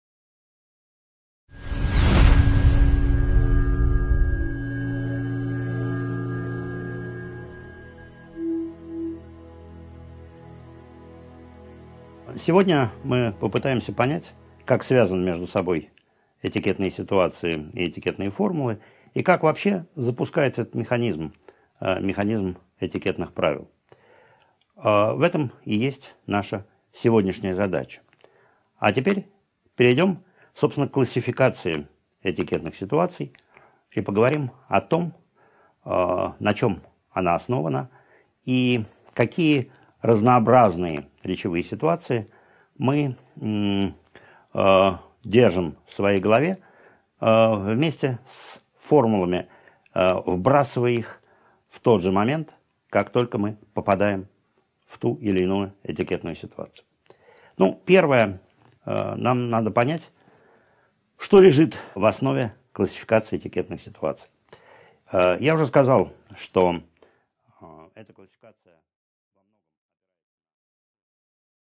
Aудиокнига 2.2 Стандартные классификация Автор Максим Анисимович Кронгауз.